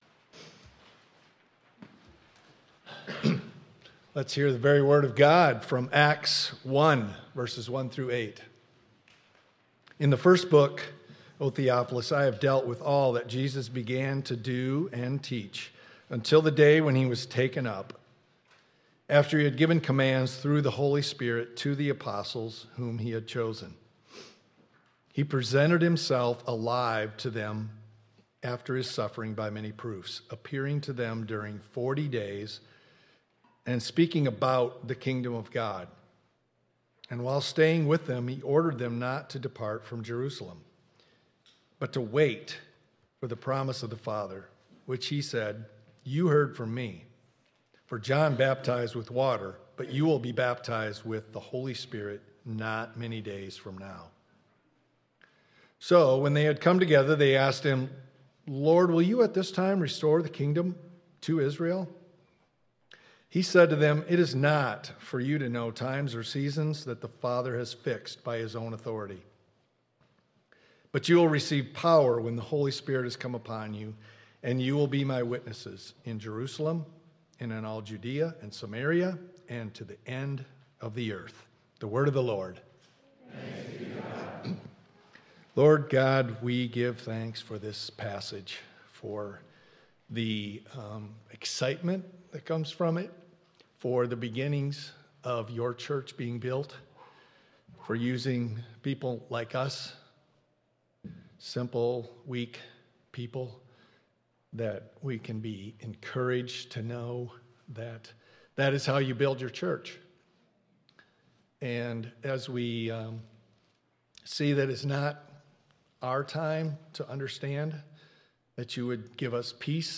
1.11.26 sermon.m4a